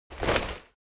holster.ogg.bak